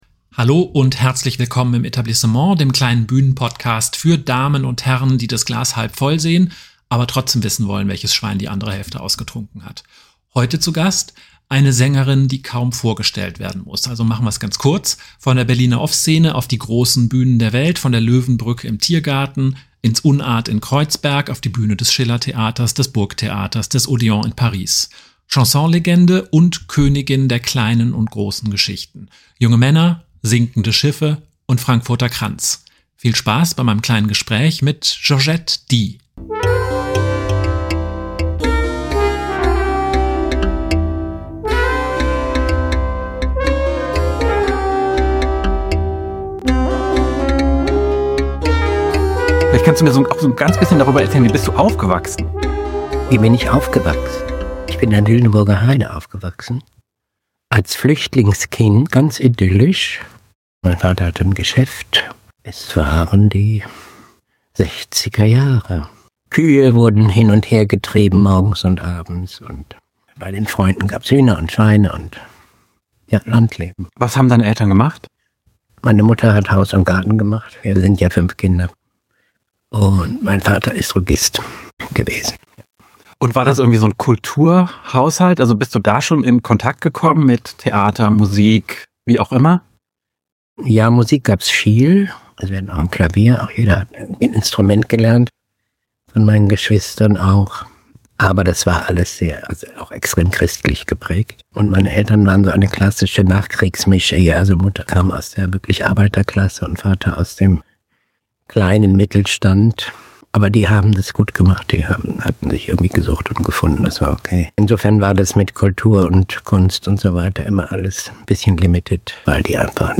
Sie ist die Königin des Chanson und der großen und kleinen Geschichten: Sängerin und Schauspielerin Georgette Dee.
In unserem kleinen Gespräch reden wir über ihre Jugend in der Lüneburger Heide, das Wursteln in der Off-Szene, den Durchbruch im Berliner Schillertheater und darüber, was sie so vom Theater denkt.